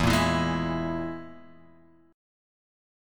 EMb5 chord {0 1 2 1 x 0} chord